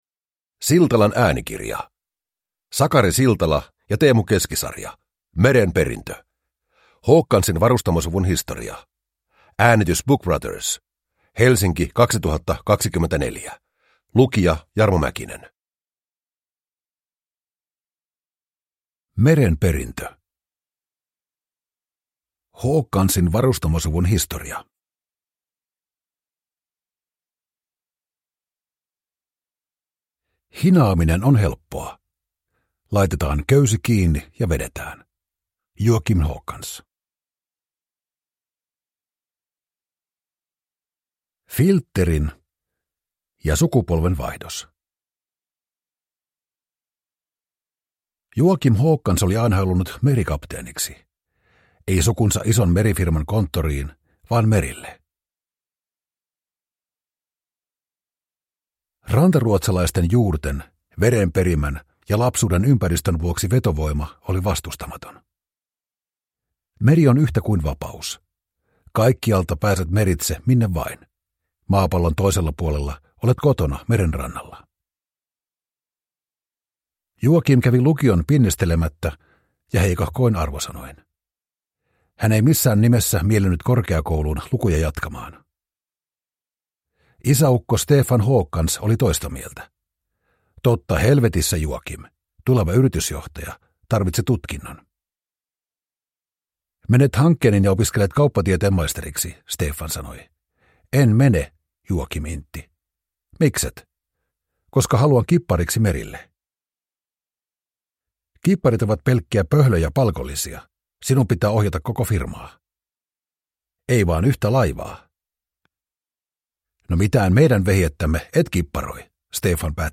Merenperintö – Ljudbok